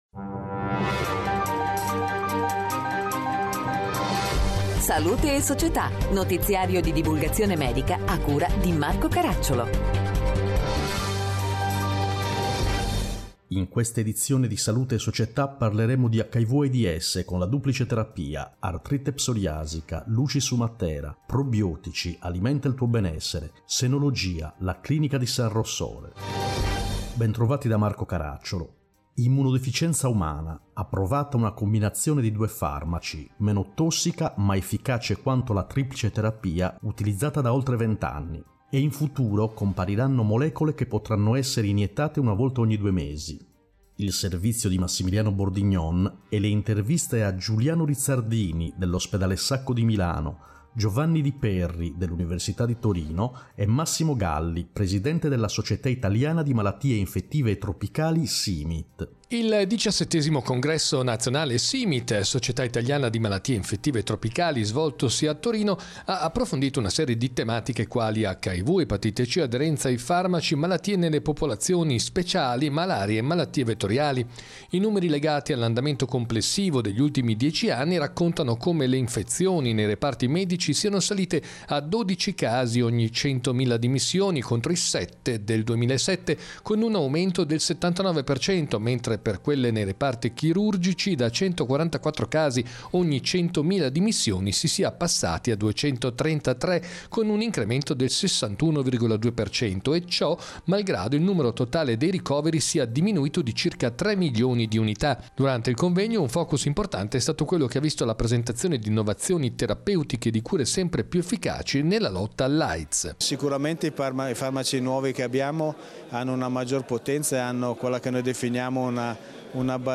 In questa edizione: 1. Hiv/Aids, Duplice terapia 2. Artrite psoriasica, “Luci su Matera” 3. Probiotici, Alimenta il tuo Benessere 4. Senologia, La clinica San Rossore Interviste